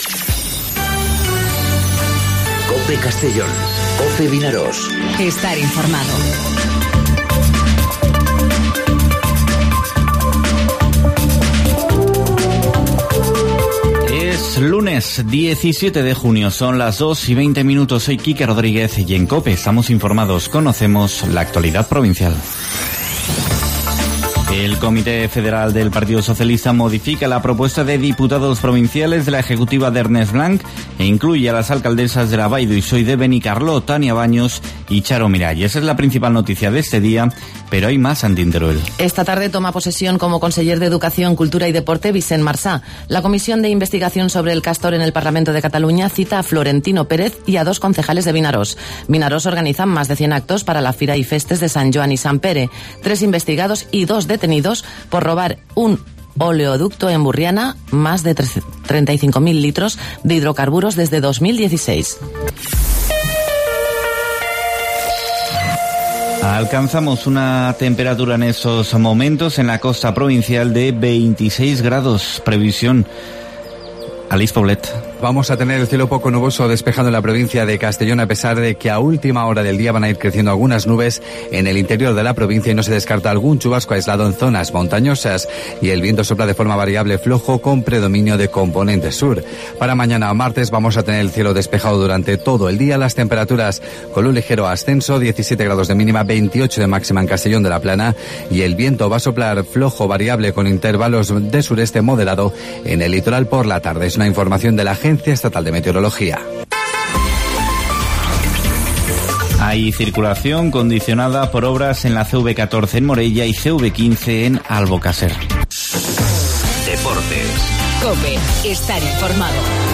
Informativo 'Mediodía COPE' en Castellón (17/06/2019)